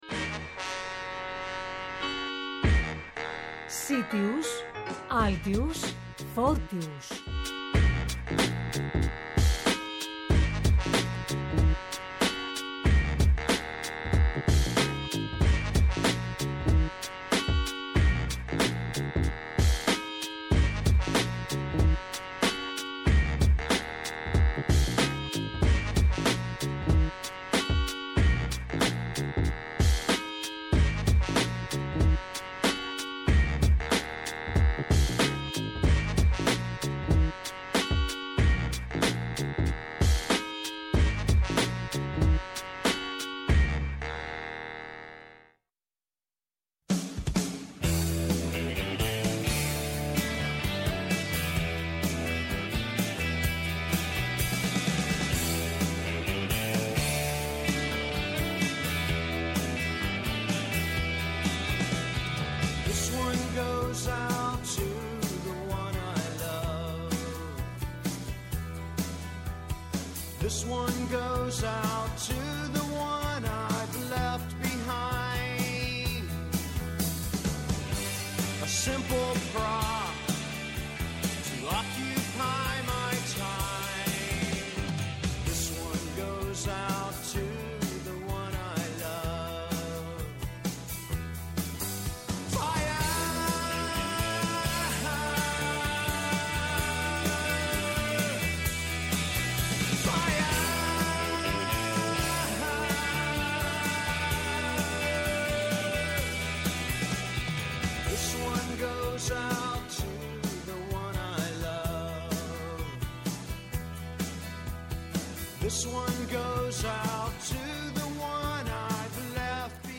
Κοντά μας σήμερα ζωντανά στο στούντιο